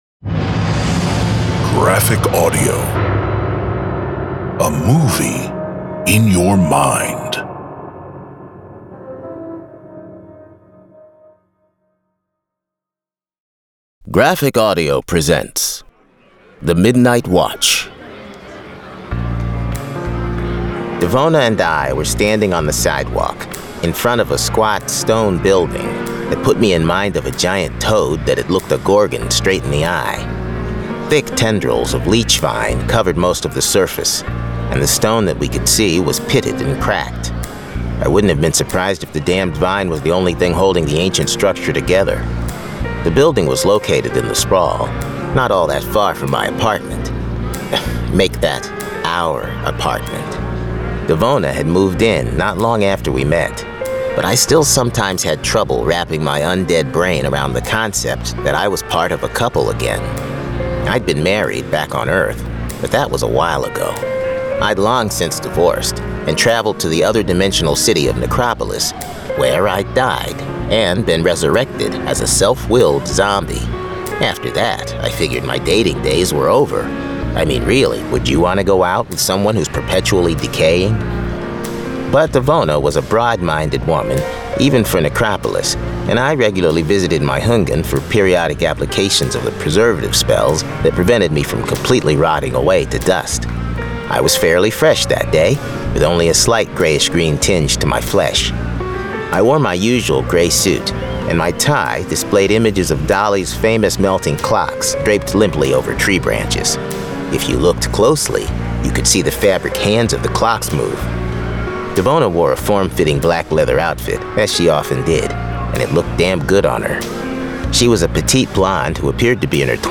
This is an actively updated collection of graphic audio material.